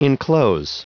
Prononciation du mot enclose en anglais (fichier audio)
Prononciation du mot : enclose